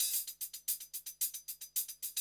HIHAT LOP8.wav